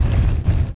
attack_act_1.wav